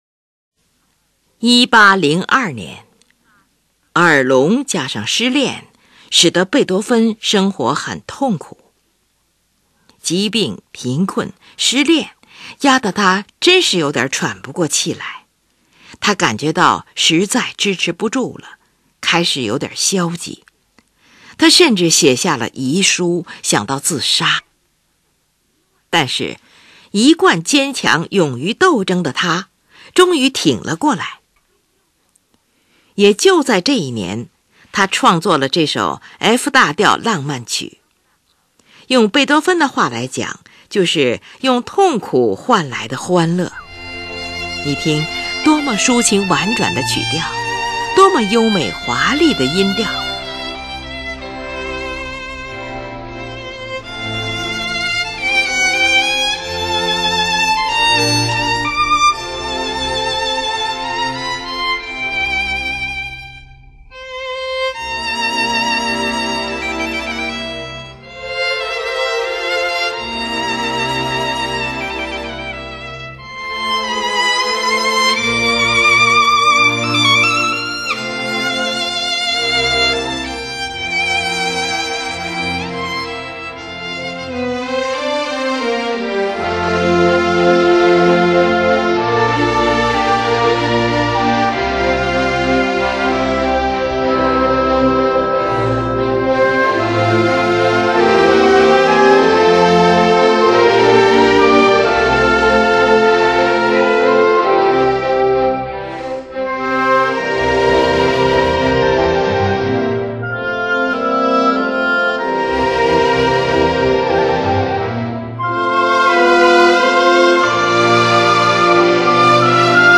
你听，多么抒情婉转的曲调，多么优美华丽的音调，后面还有坚强的力度。